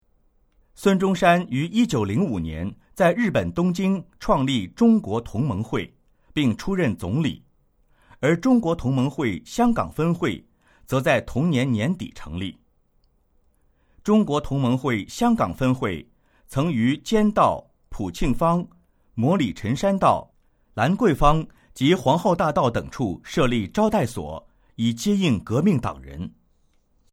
语音简介